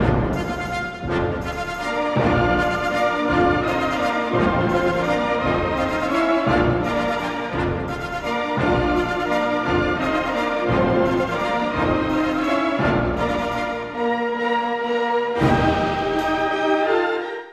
最初の「美しい娘さん」の民謡が力強く奏でられます。
とてもスケールが大きくのびのびとした部分です！